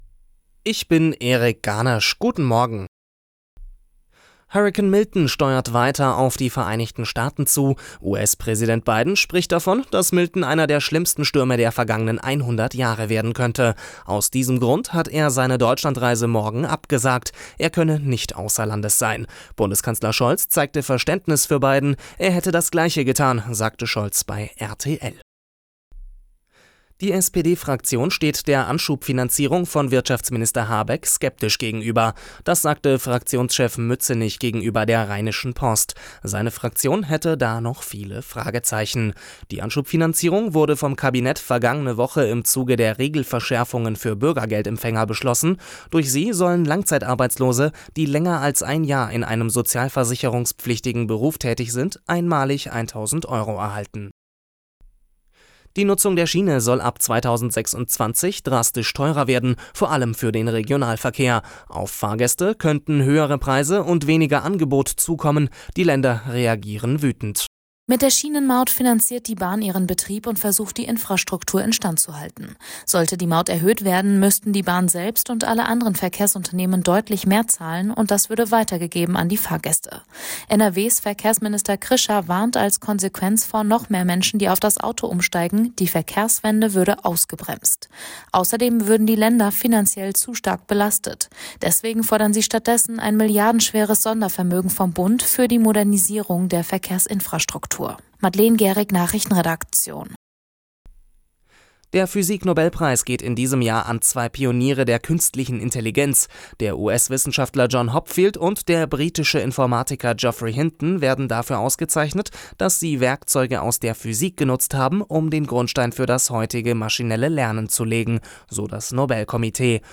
Die Arabella Nachrichten vom Mittwoch, 9.10.2024 um 04:59 Uhr - 09.10.2024